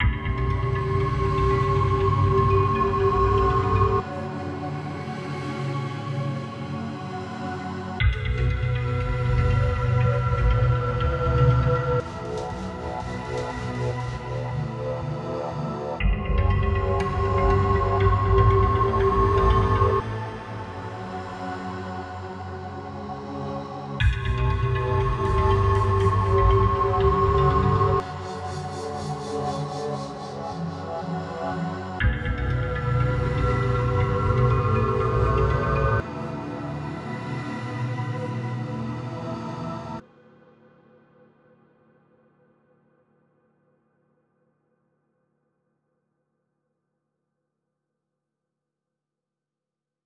Free ambient music loop; credit required